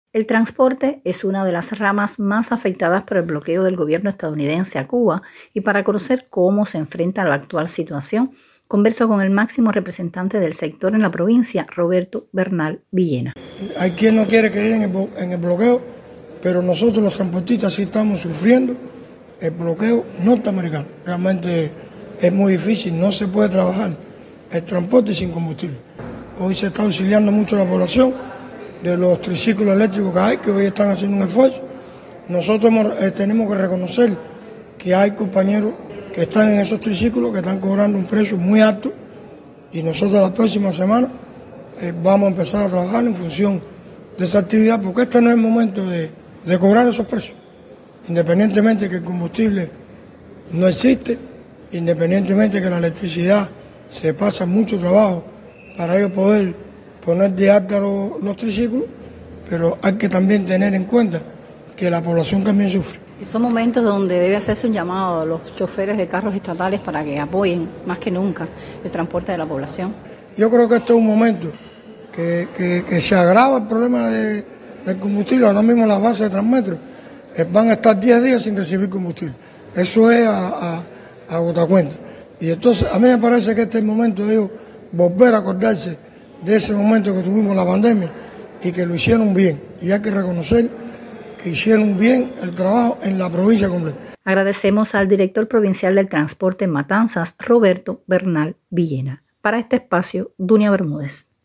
Para conocer cómo se enfrenta la actual crisis energética converso con el máximo representante del sector en la provincia de Matanzas, Roberto Bernal Viillena.